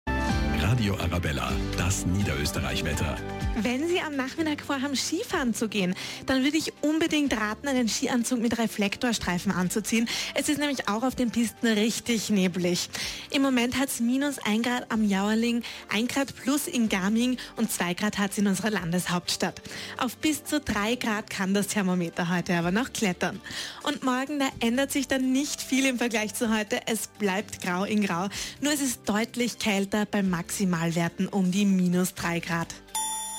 wienerisch
Sprechprobe: Sonstiges (Muttersprache):
If you are looking for a young, modern and powerful female voice don`t hesitate to contact me for a free demo.